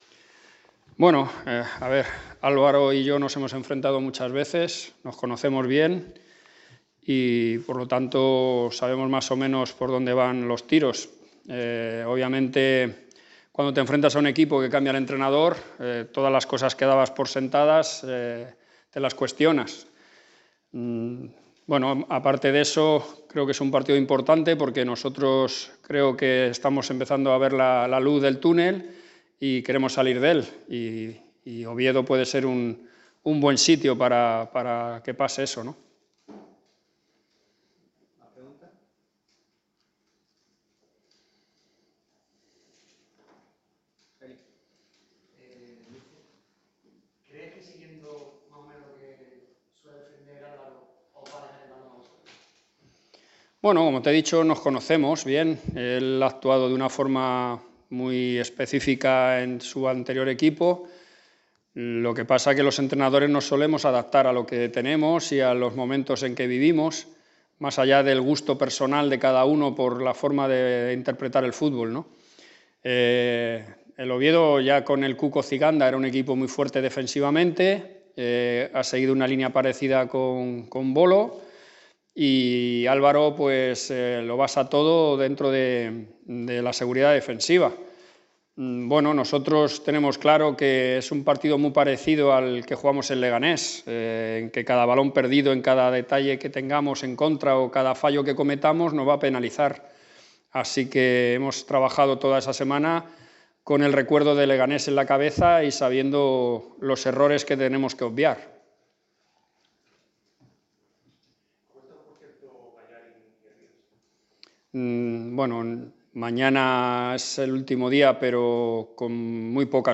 Pepe Mel, entrenador del Málaga CF ha comparecido ante los medios en la rueda de prensa previa al partido del lunes contra el Oviedo en el Carlos Tartiere. El técnico madrileño comentó el posible planteamiento contra el conjunto asturiano, la rivalidad con Cervera (nuevo entrenador del rival), la importancia del banquillo y de los jóvenes; además, del nuevo fichaje del Málaga, Lumor.